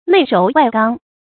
內柔外剛 注音： ㄣㄟˋ ㄖㄡˊ ㄨㄞˋ ㄍㄤ 讀音讀法： 意思解釋： 內心柔弱，外表剛強。